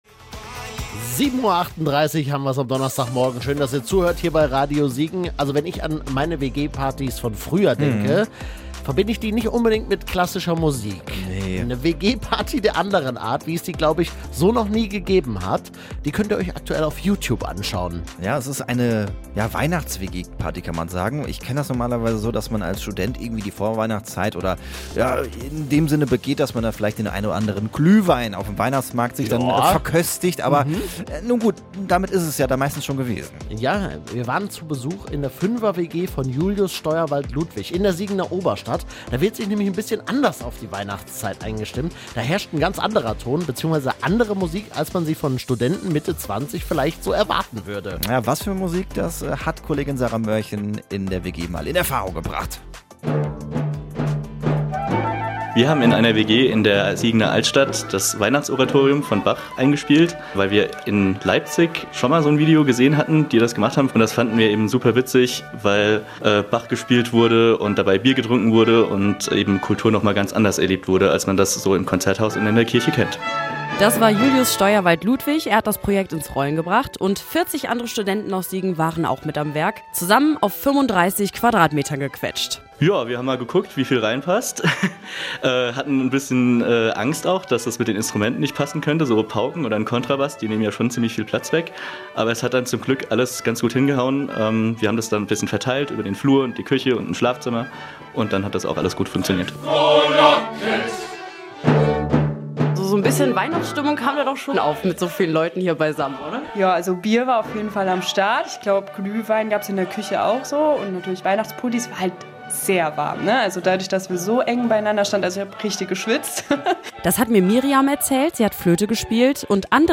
flor---mitschnitt-weihnachtsoratorium-in-siegener-wg.mp3